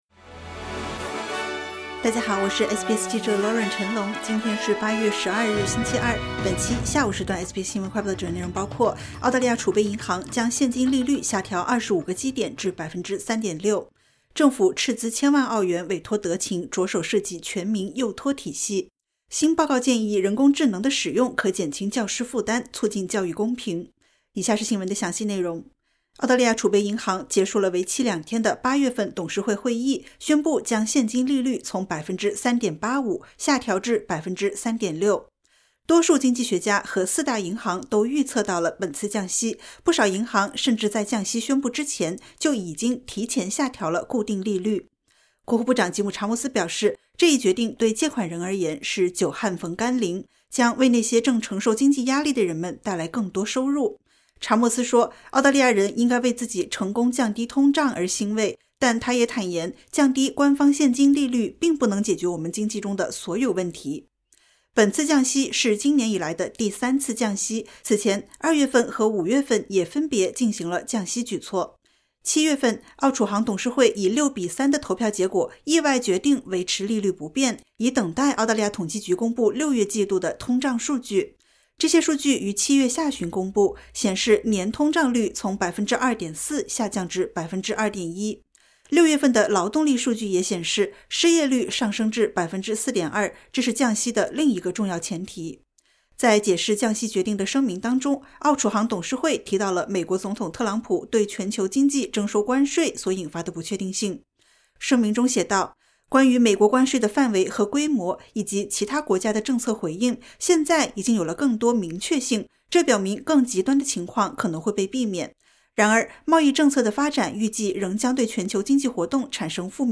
【SBS新闻快报】澳储行将现金利率下调25个基点至3.6%